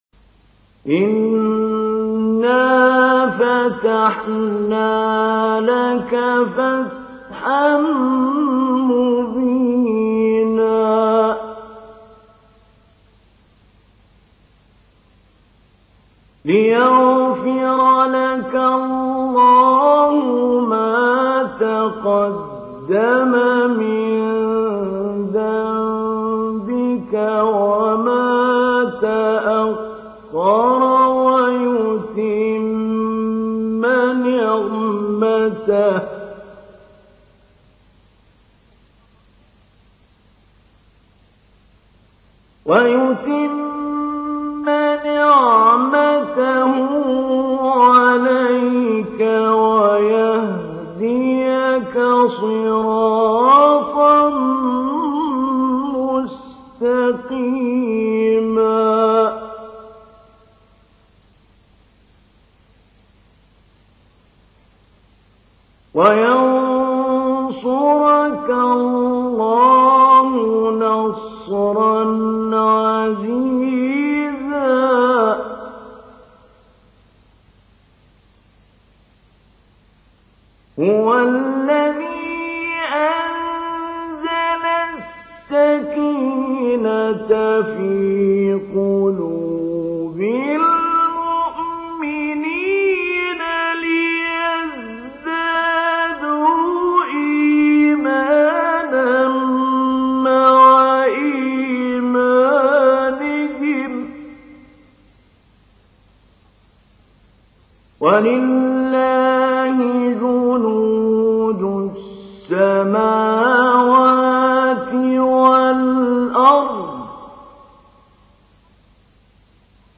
تحميل : 48. سورة الفتح / القارئ محمود علي البنا / القرآن الكريم / موقع يا حسين